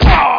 plyrhit3.mp3